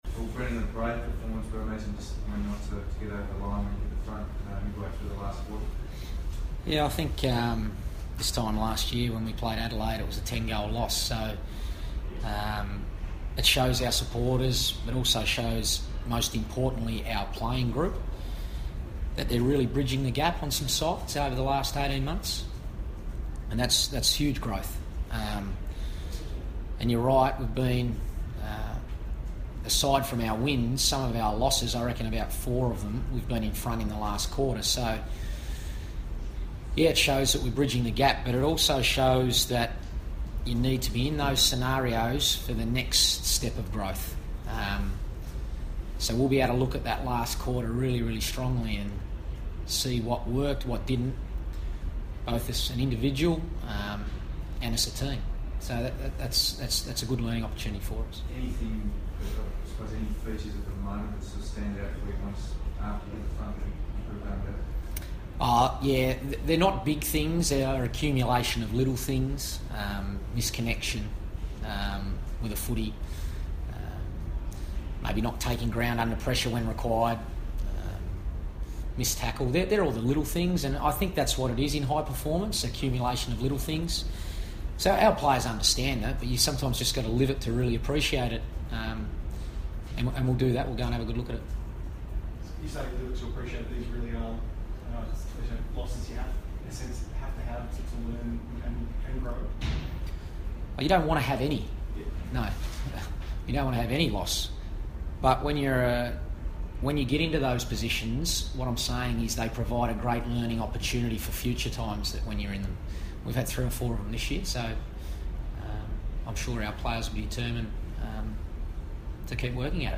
Carlton coach Brendon Bolton fronts the media after the Blues' 12-point loss to Adelaide.